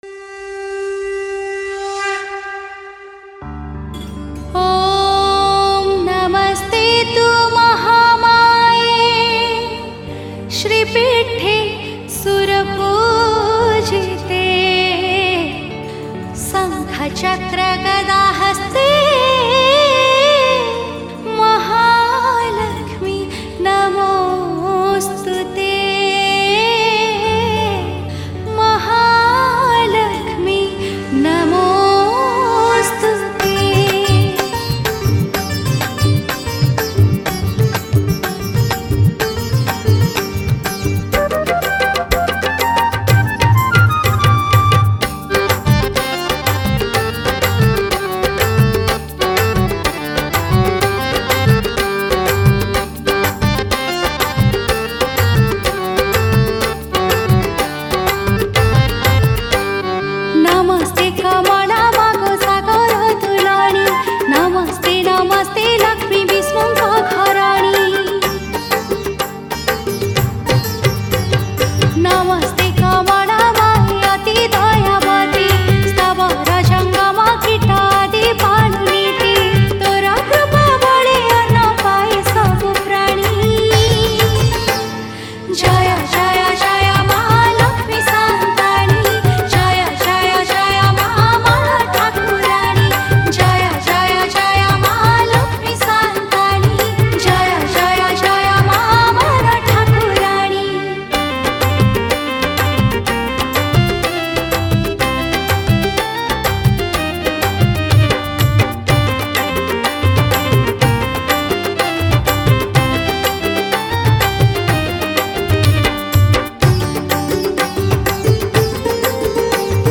Manabasa Gurubara Bhajan